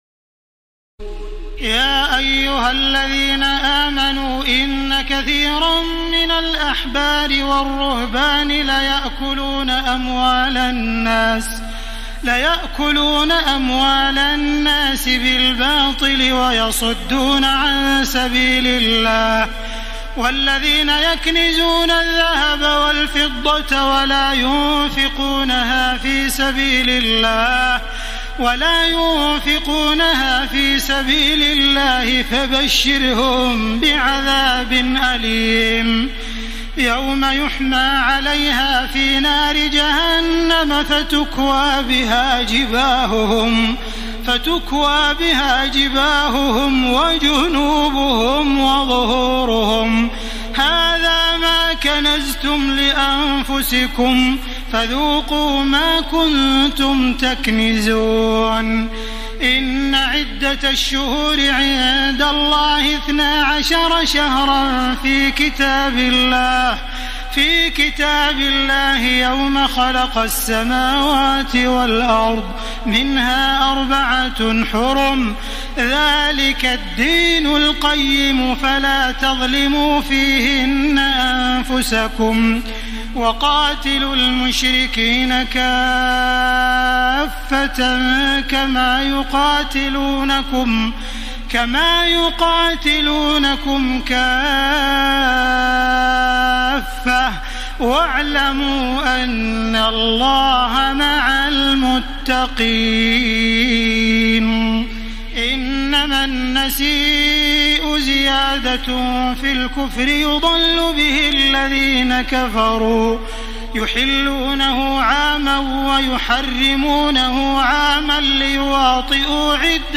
تراويح الليلة التاسعة رمضان 1434هـ من سورة التوبة (34-93) Taraweeh 9 st night Ramadan 1434H from Surah At-Tawba > تراويح الحرم المكي عام 1434 🕋 > التراويح - تلاوات الحرمين